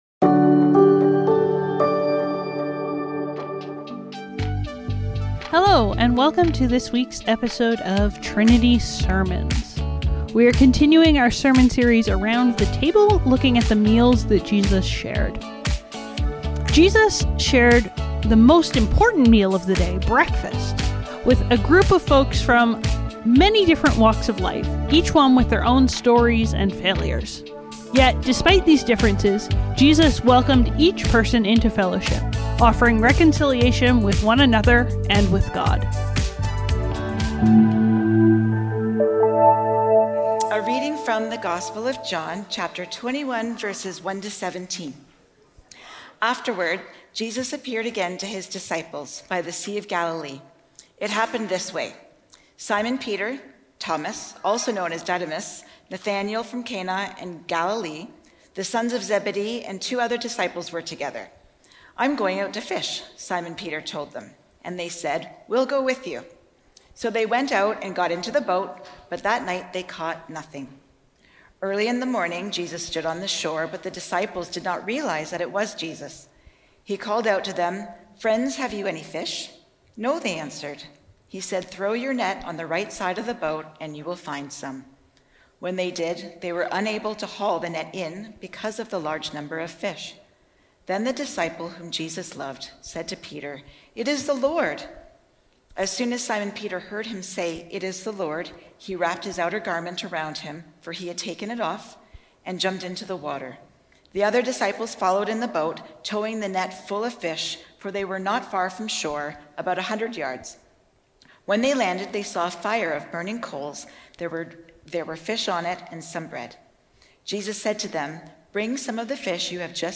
Trinity Streetsville - The Breakfast Club | Around The Table | Trinity Sermons